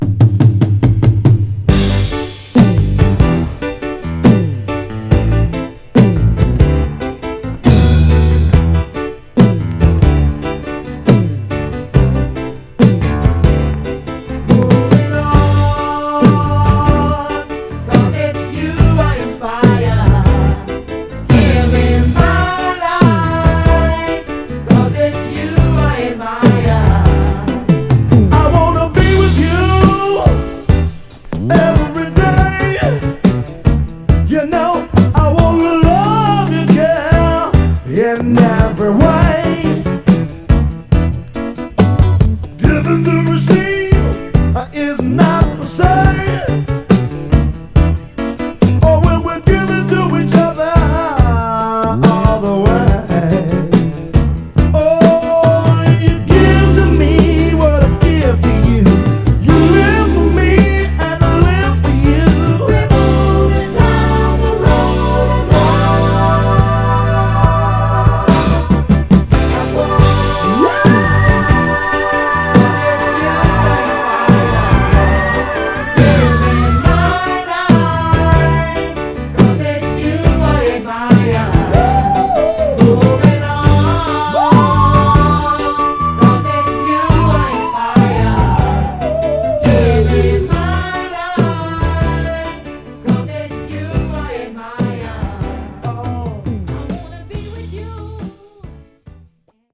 モダンソウル期の彼らしさ爆発のメロウ・フュージョンです！
※試聴音源は実際にお送りする商品から録音したものです※